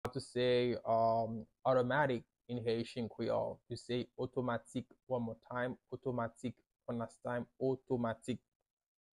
“Automatic” in Haitian Creole – “Otomatik” pronunciation by a native Haitian teacher
“Otomatik” Pronunciation in Haitian Creole by a native Haitian can be heard in the audio here or in the video below:
How-to-say-Automatic-in-Haitian-Creole-–-Otomatik-pronunciation-by-a-native-Haitian-teacher.mp3